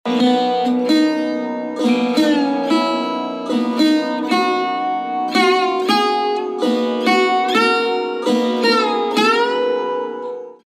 Raga
ArohaS G R m G P M D P N D S’
Gaud Sarang (Aroha)